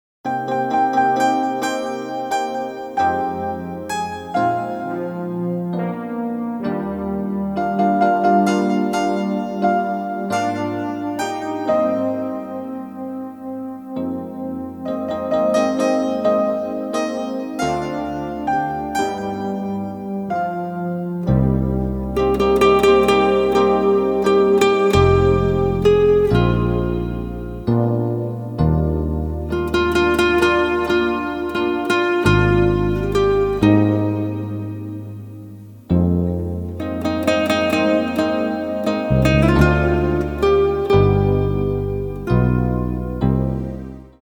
• Качество: 192, Stereo
гитара
спокойные
без слов
инструментальные
пианино